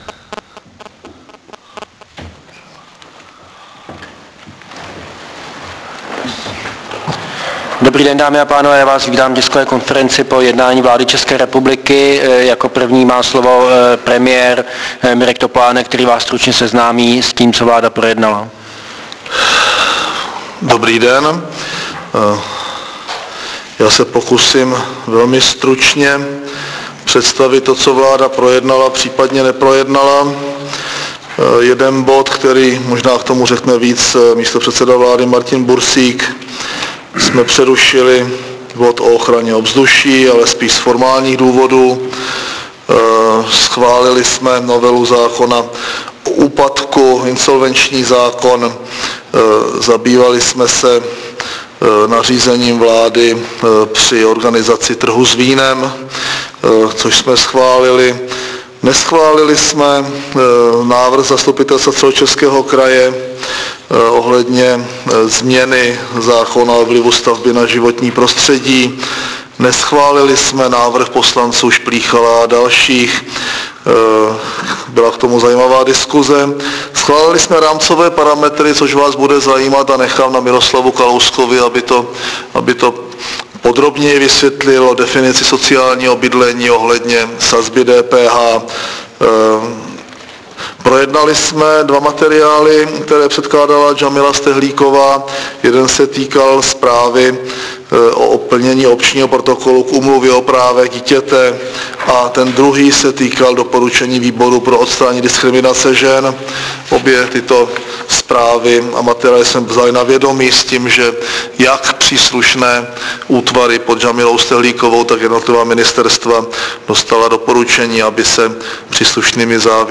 Tisková konference předsedy vlády ČR Mirka Topolánka po jednání vlády v pondělí 5.2.2007